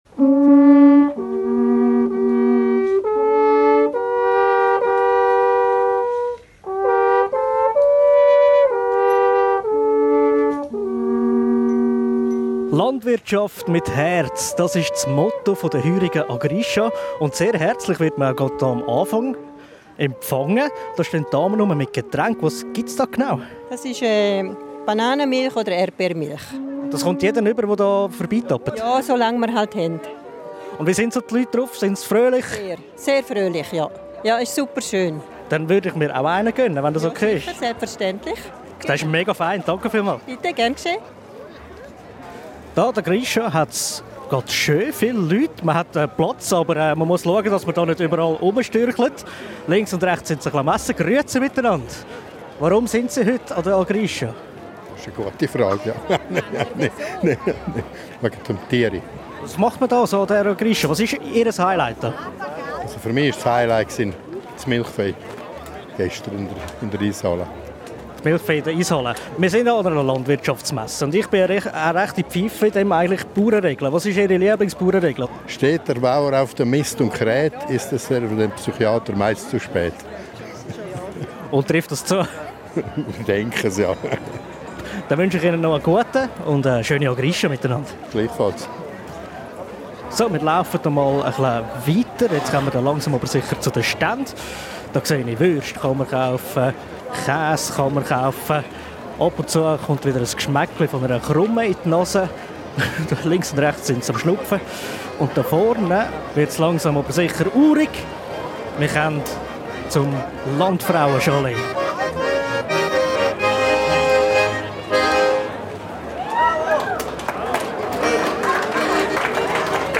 Eine Reportage.